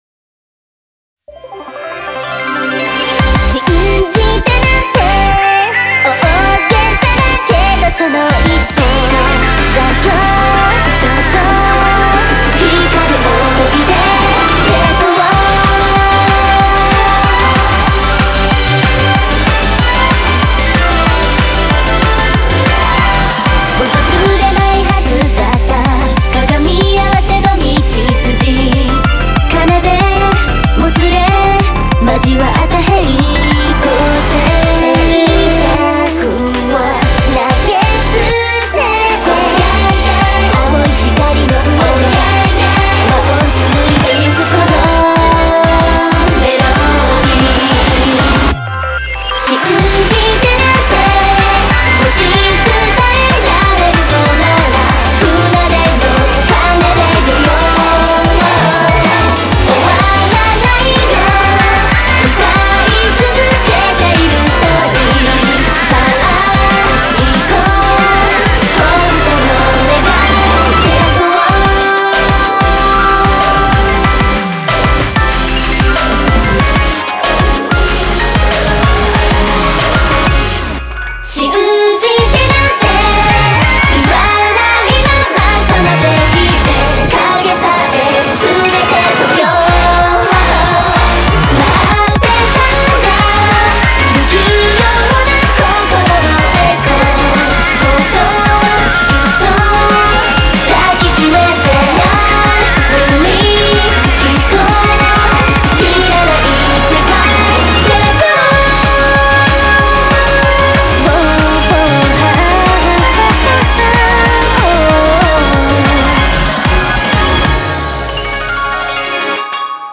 BPM188
Audio QualityPerfect (High Quality)
Genre: Technically Weeb Music